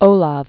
(ōläv)